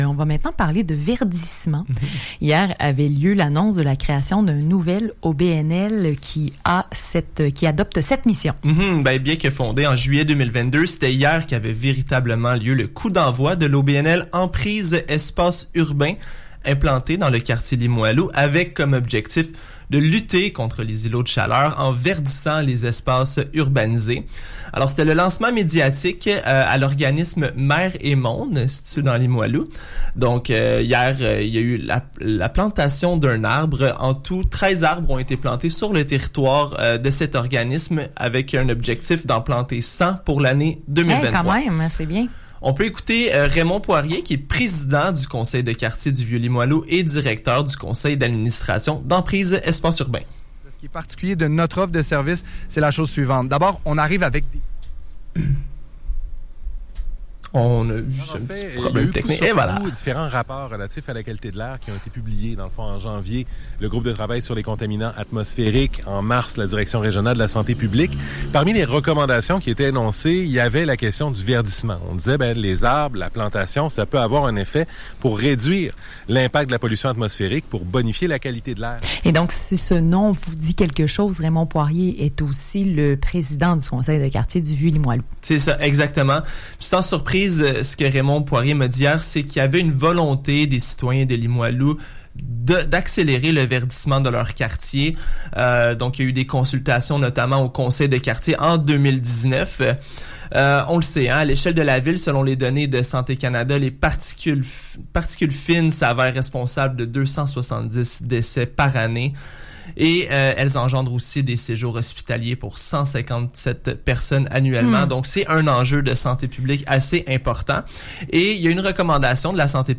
Reportage à l’émission Québec réveille:
Reportage-Emprise.mp3